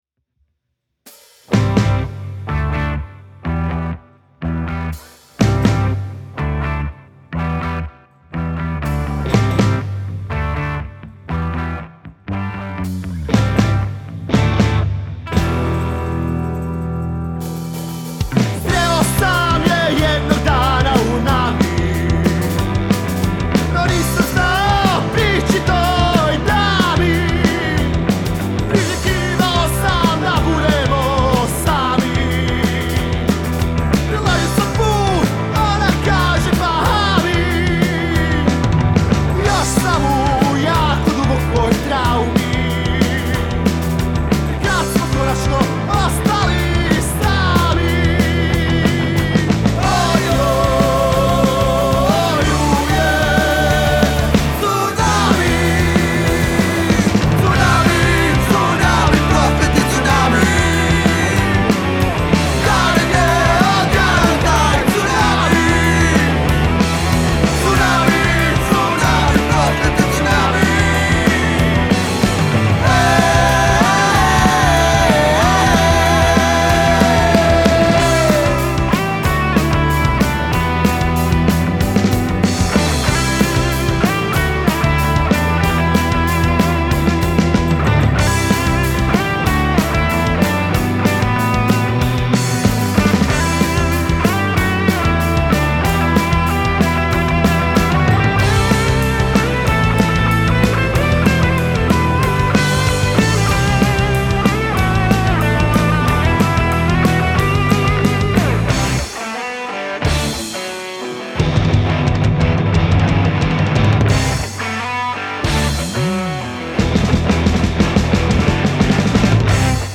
Punkerski power metal. Epic moćna i pjevna pjesmica